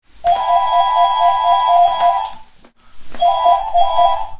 电话铃声
描述：使用Zoom H6录制声音。这是一个旧的电话铃声
Tag: 复古 电话 OWI 电话 铃声